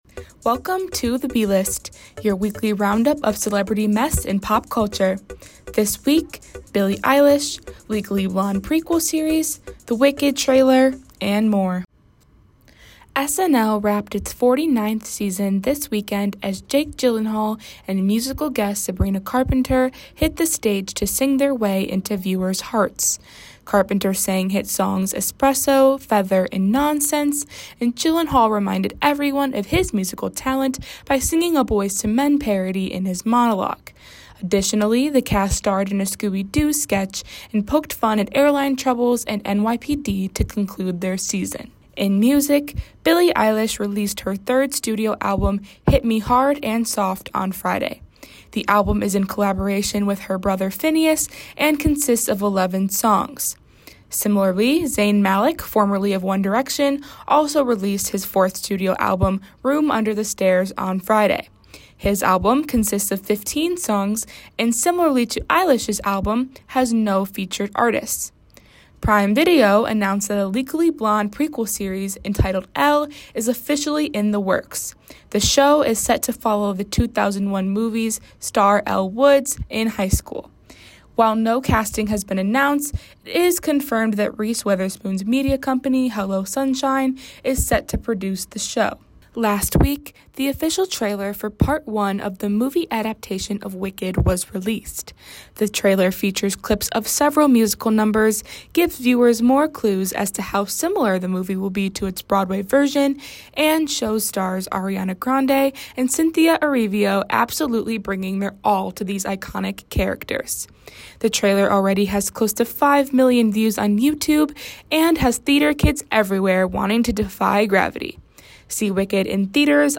Theme music is from MixKit “What What?! Wowow!” by Michael Ramir C.